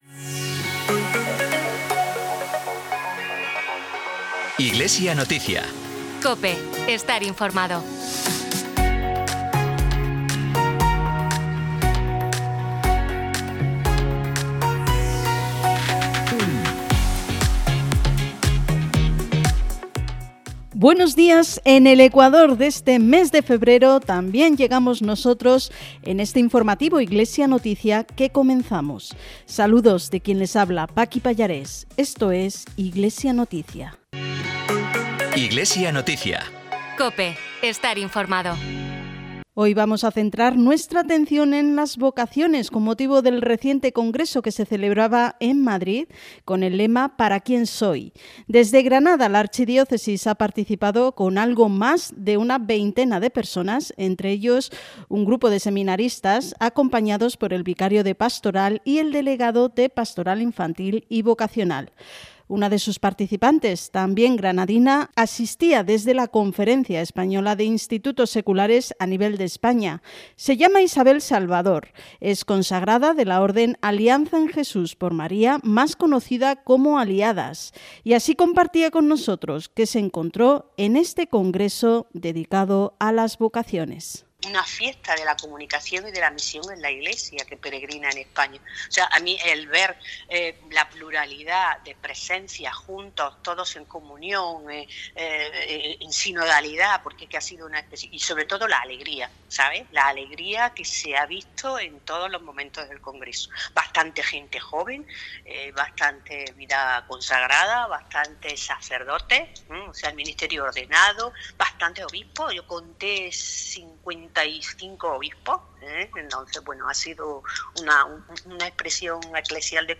Programa emitido en COPE Granada y COPE Motril el 16 de febrero de 2025.